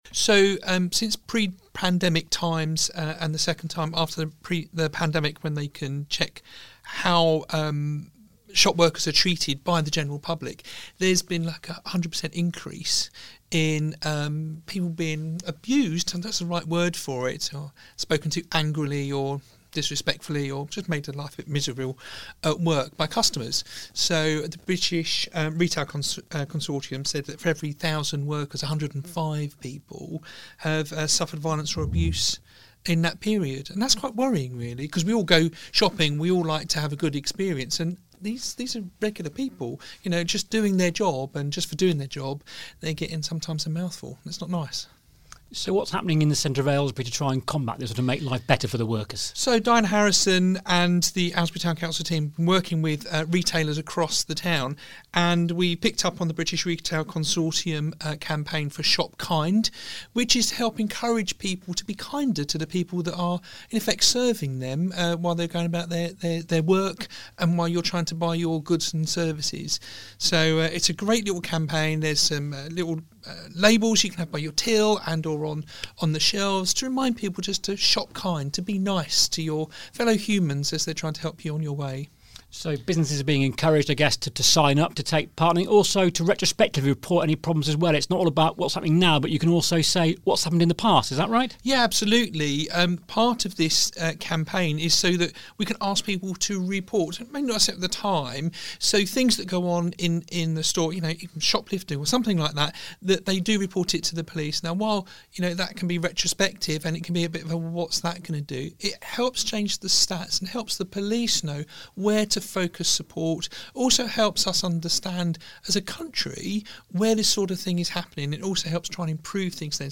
We've been speaking to the Town's Mayor, Cllr Steven Lambert, about the scheme, and the support available for Town Centre businesses.